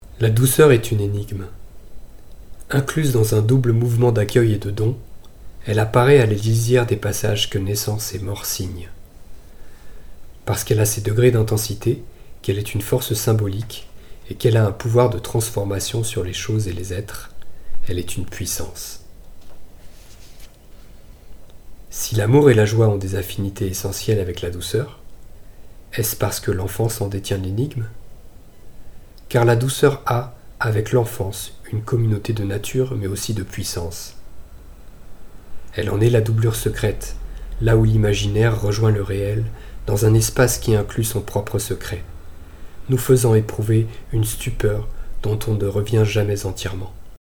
Voix Off 1 - La douceur
34 - 50 ans - Contre-ténor